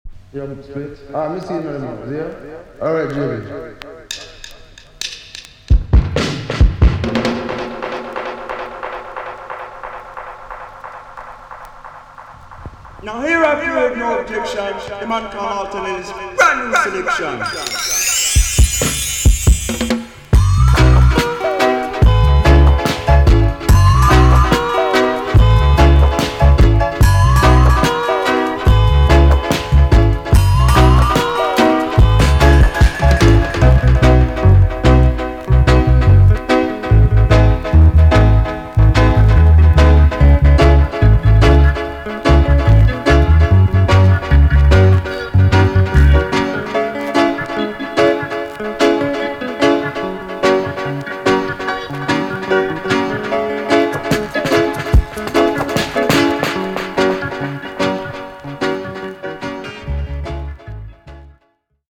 B.SIDE Version
EX- 音はキレイです。